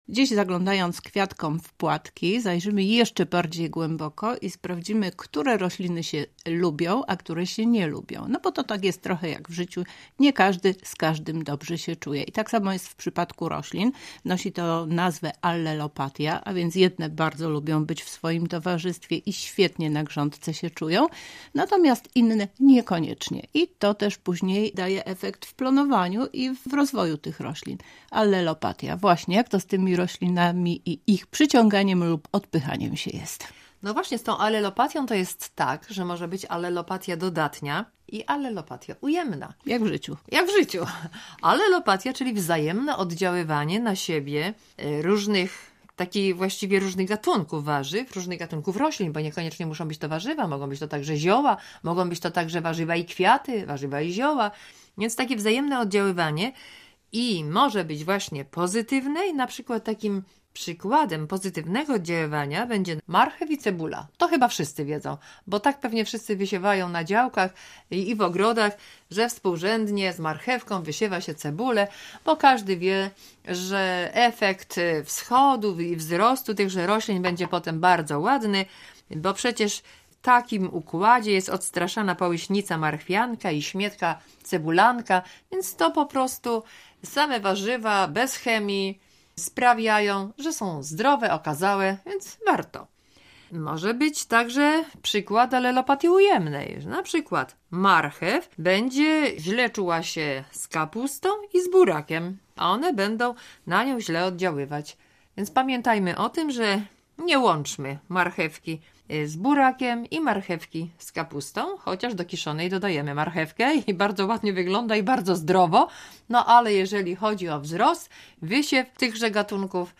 Więcej w rozmowie